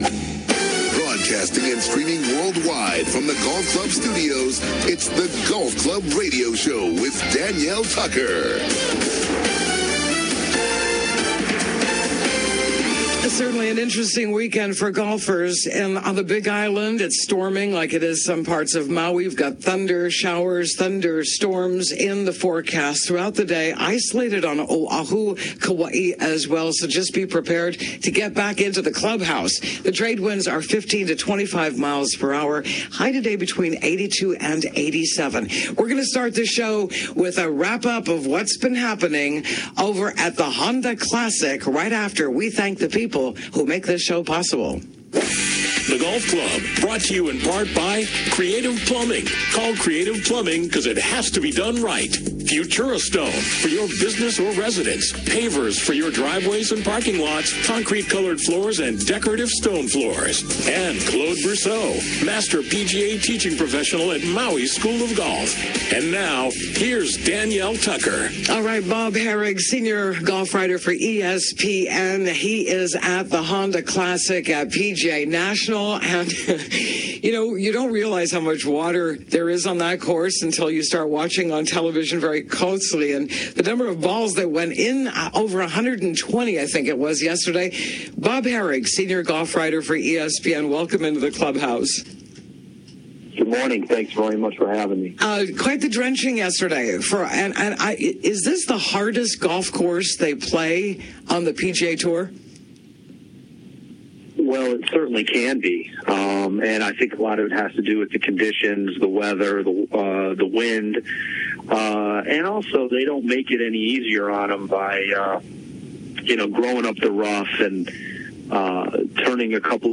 Live SATURDAY MORNINGS